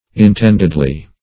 intendedly - definition of intendedly - synonyms, pronunciation, spelling from Free Dictionary
intendedly - definition of intendedly - synonyms, pronunciation, spelling from Free Dictionary Search Result for " intendedly" : The Collaborative International Dictionary of English v.0.48: Intendedly \In*tend"ed*ly\, adv.